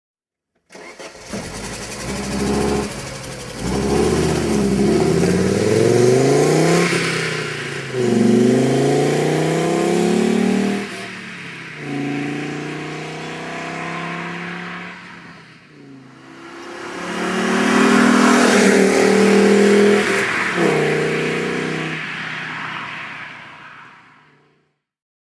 Audi quattro (1981) - Soundkulisse
Sound_Audi_Quattro_1981.mp3